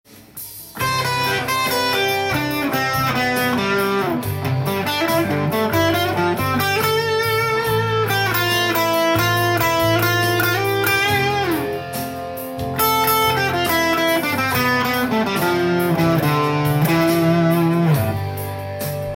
①、②、③のフレーズを使いソロを弾いてみた